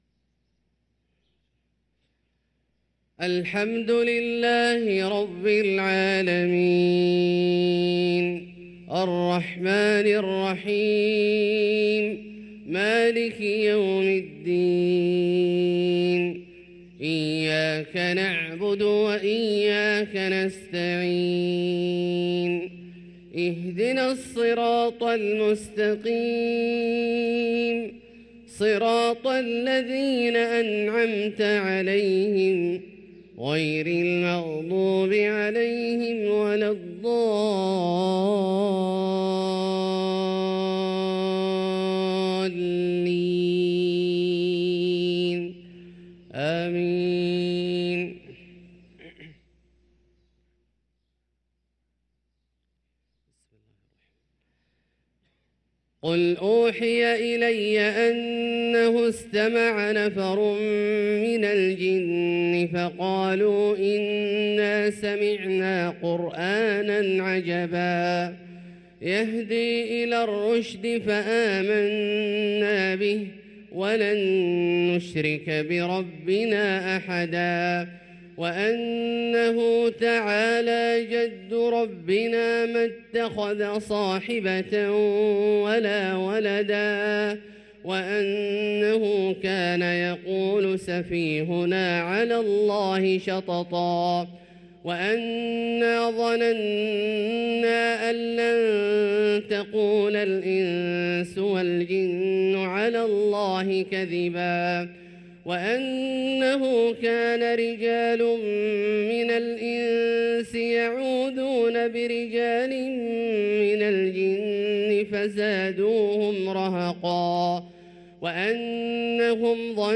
صلاة الفجر للقارئ عبدالله الجهني 26 جمادي الأول 1445 هـ